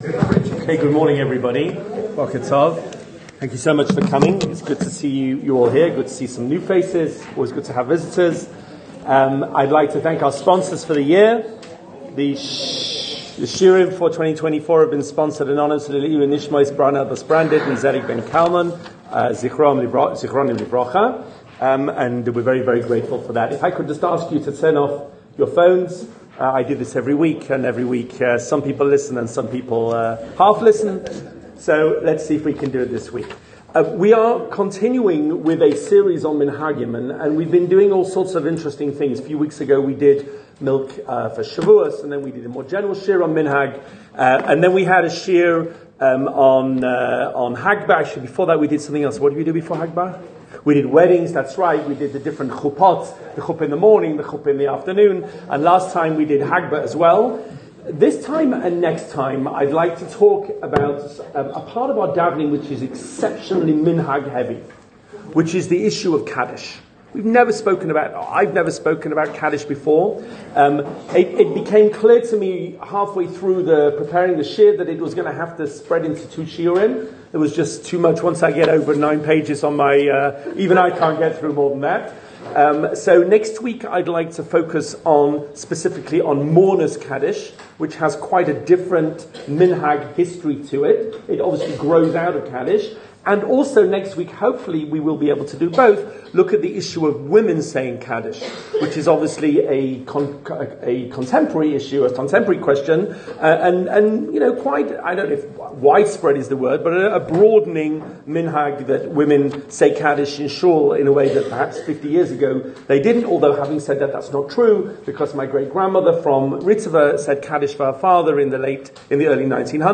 A series of 3 lectures on the development of Kaddish in the synagogue service: The Origins of Kaddish, Mourner's Kaddish, Women Saying Kaddish